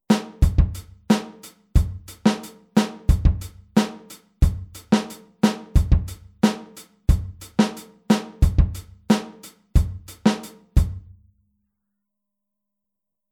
Anders als die Wechselschläge bei 16tel spielt die rechte Hand durchgängig alle 8tel.
Groove08-8tel.mp3